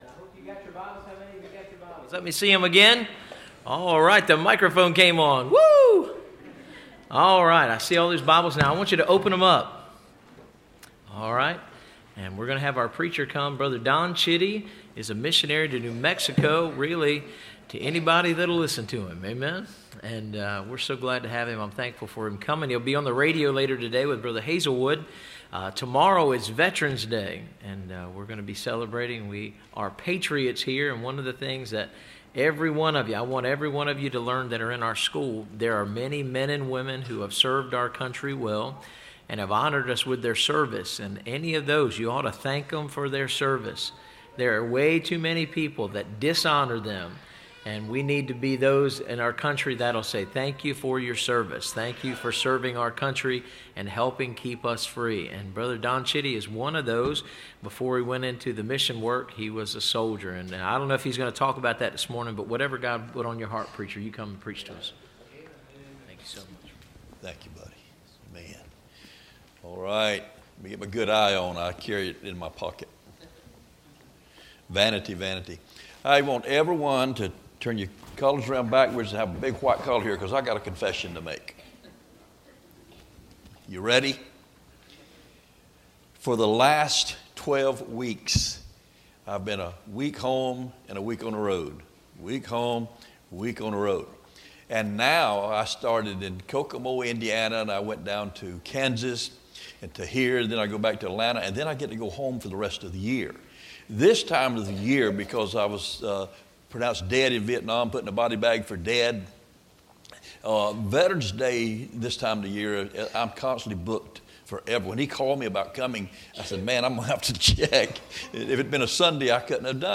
Listen to Message
Service Type: Missions Conference